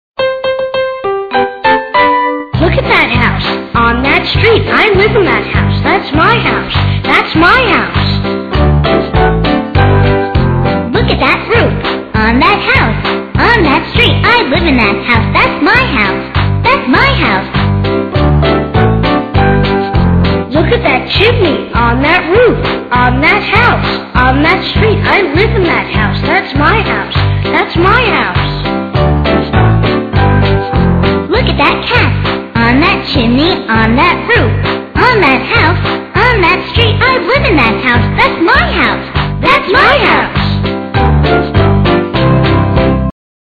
在线英语听力室英语儿歌274首 第210期:That's My House的听力文件下载,收录了274首发音地道纯正，音乐节奏活泼动人的英文儿歌，从小培养对英语的爱好，为以后萌娃学习更多的英语知识，打下坚实的基础。